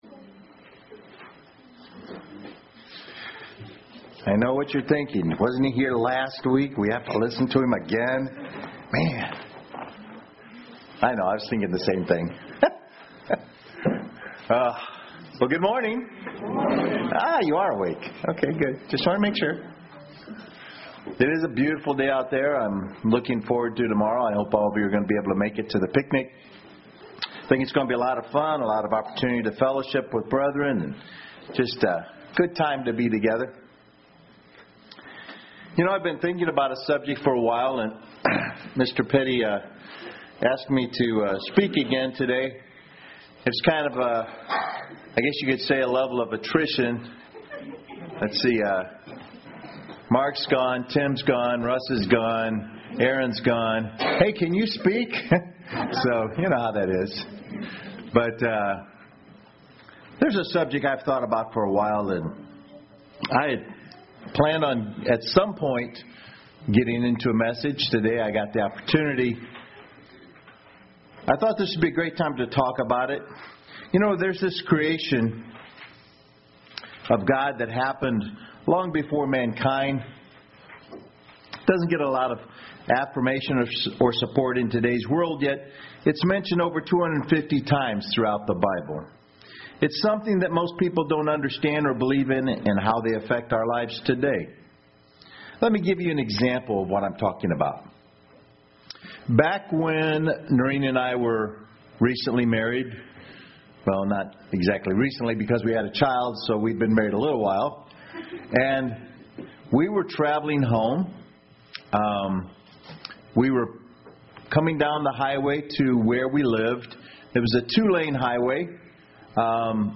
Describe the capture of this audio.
Given in Murfreesboro, TN